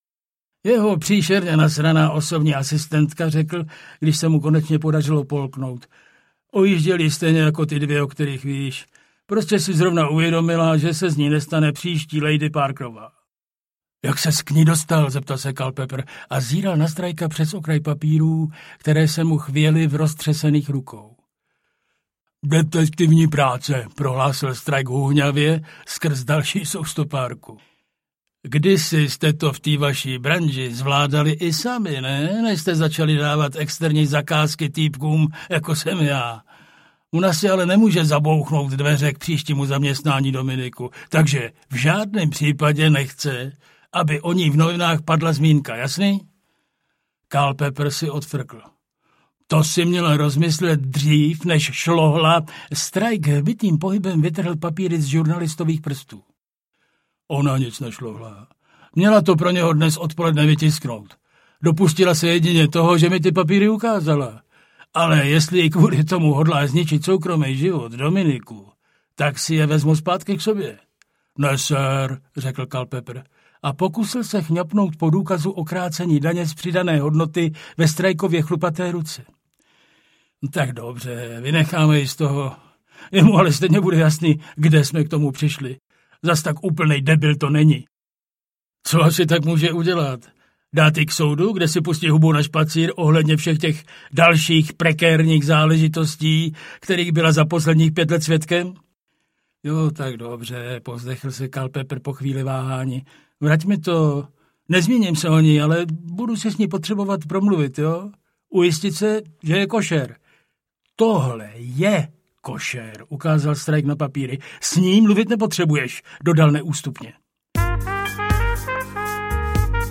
Hedvábník audiokniha
Ukázka z knihy